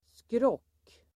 Ladda ner uttalet
Folkets service: skrock skrock substantiv, superstition Uttal: [skråk:] Böjningar: skrocket Synonymer: vidskepelse, vidskeplighet Definition: vidskepelse Sammansättningar: skrock|full (superstitious)